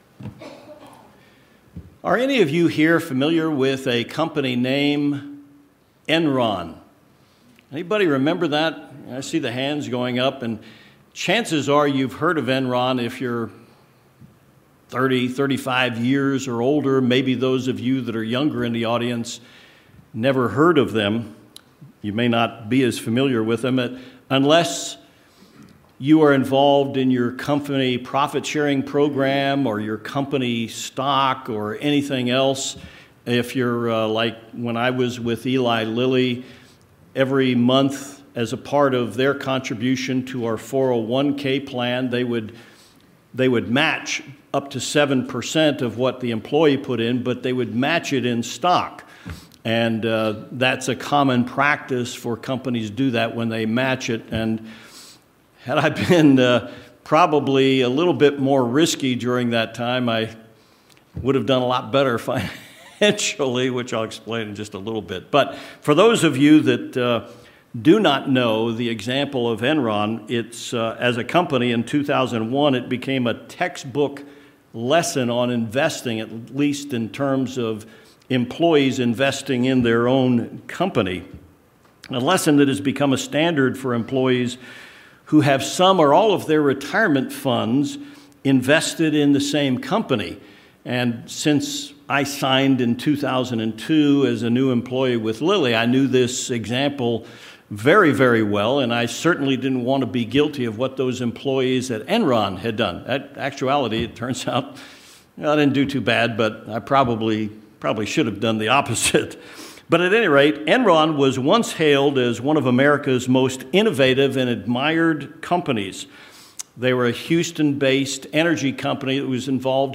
This sermon examines knowledge, wisdom and understanding, and how they all fit together to help us grow spiritually.